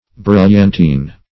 Brilliantine \Bril"lian*tine\, n. [F. brillantine.